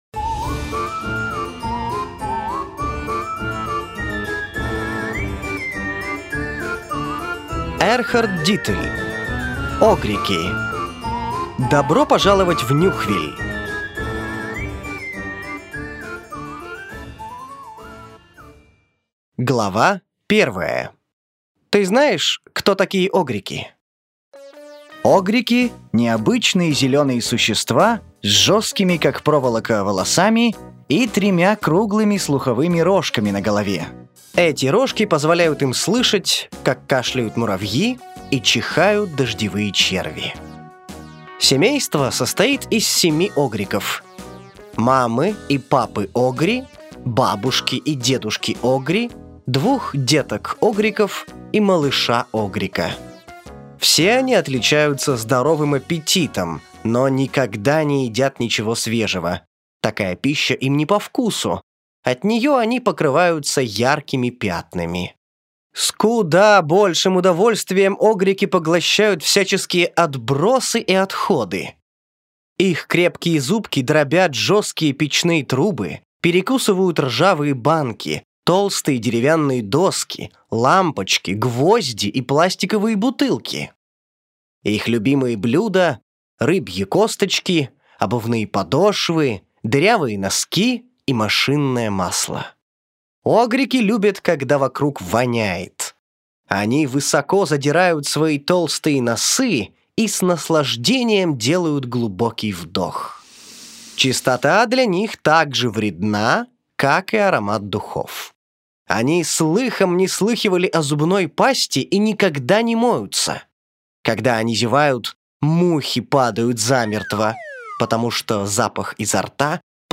Аудиокнига Огрики: Добро пожаловать в Нюхвиль!